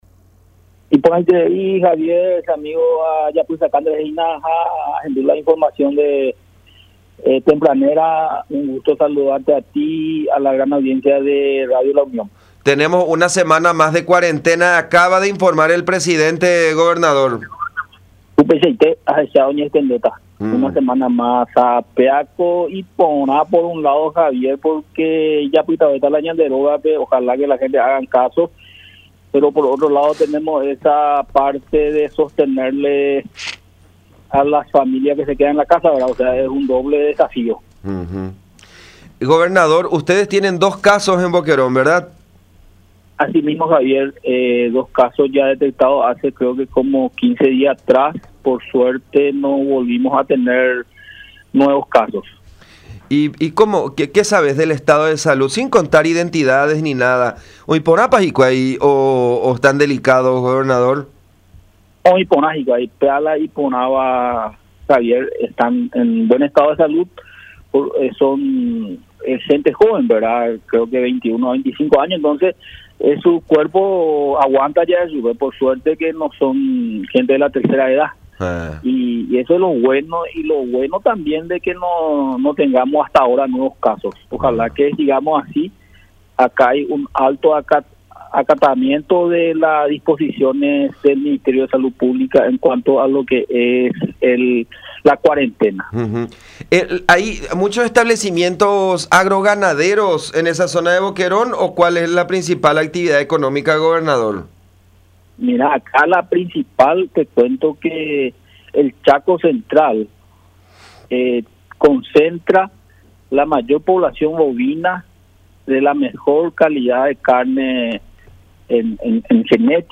Darío Medina, gobernador de Boquerón, comentó que la idea surgió a través de la oficina del Despacho de la Primera Dama en ese departamento.